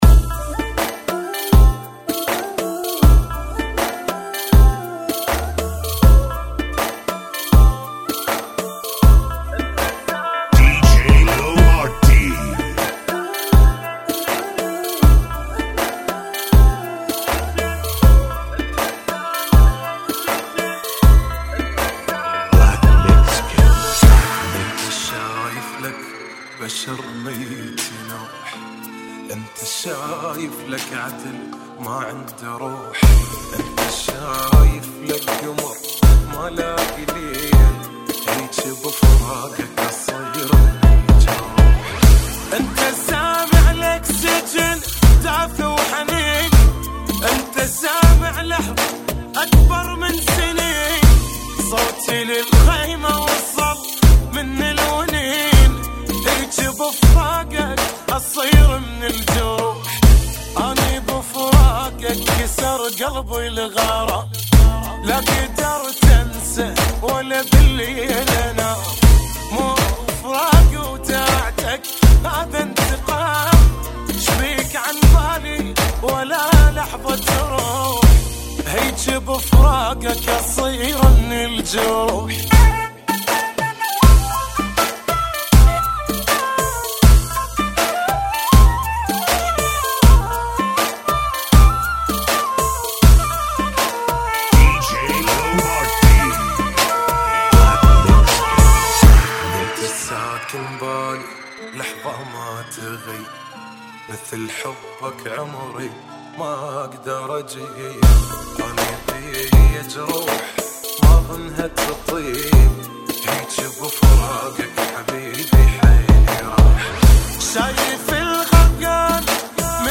Funky ( Bbm 80